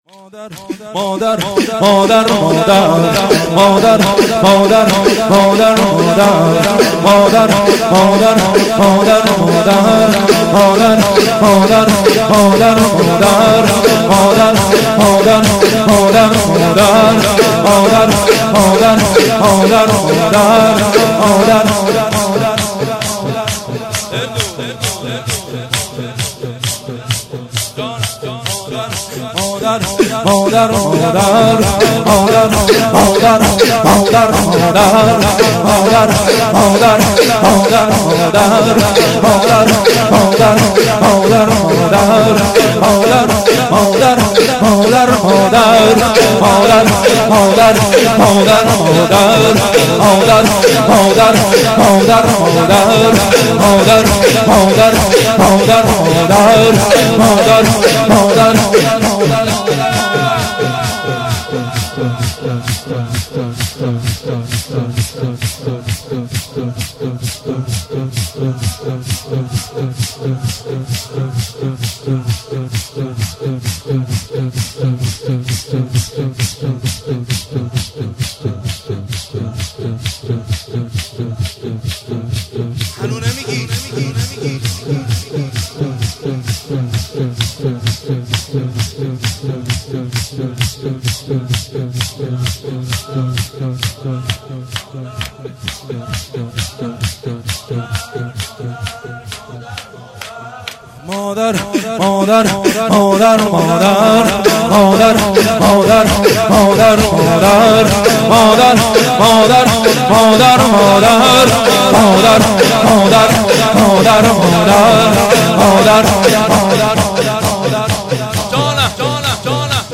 شور
هیئت زواراباالمهدی(ع) بابلسر
توسل هفتگی-روضه حضرت زهرا(س)